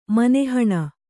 ♪ mana haṇa